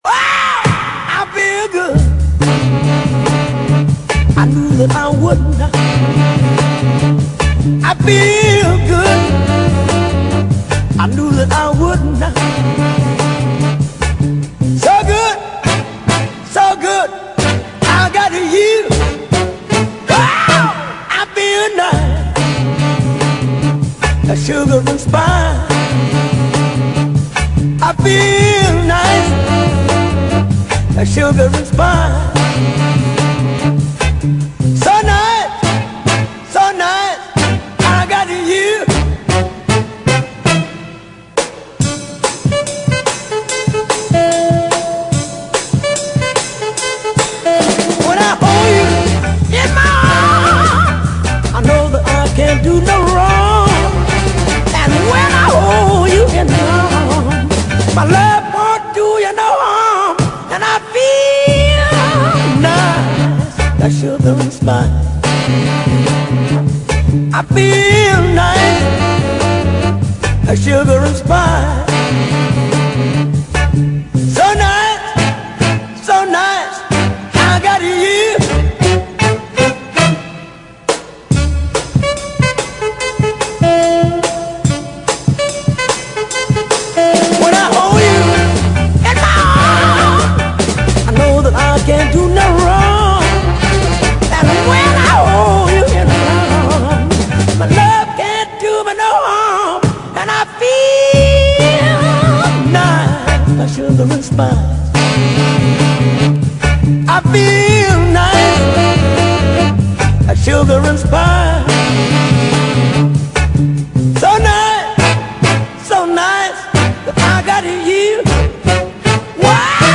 Jazz Blues Para Ouvir: Clik na Musica.